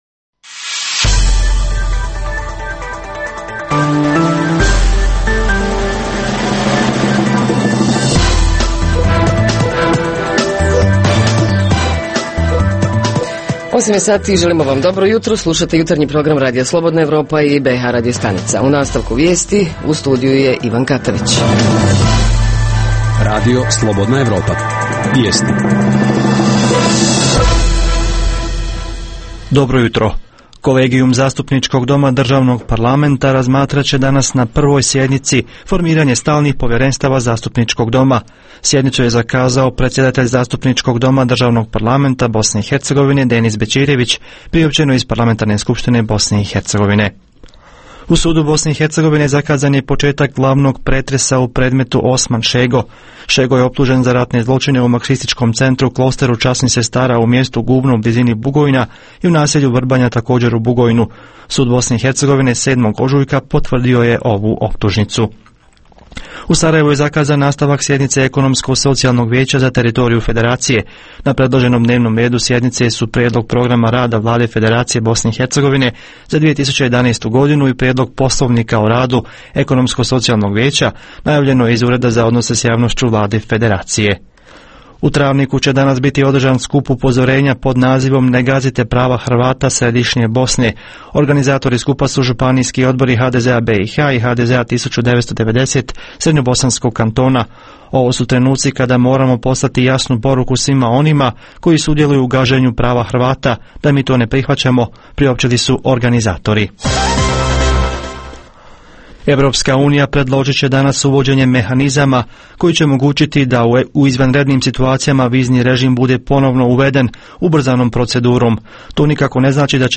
Reporteri iz cijele BiH javljaju o najaktuelnijim događajima u njihovim sredinama. Redovna rubrika Radija 27 utorkom je "Svijet interneta".